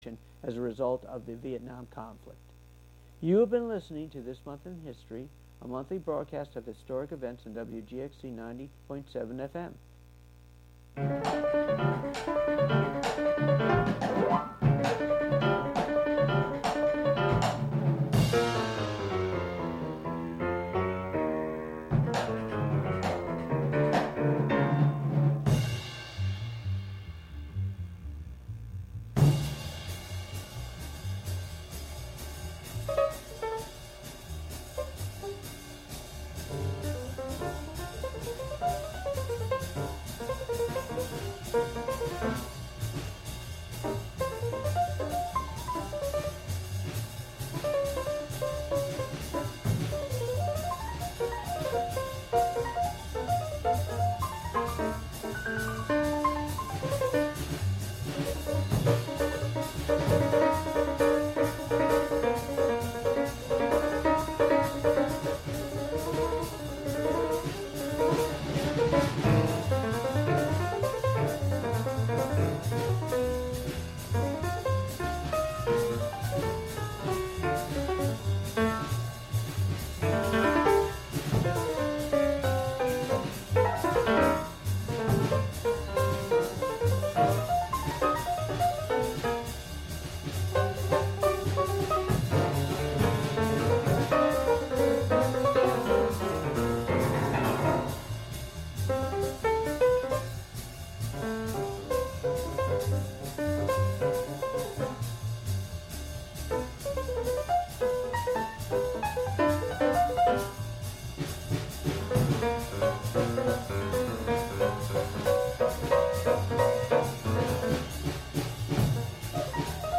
Broadcast from Catskill.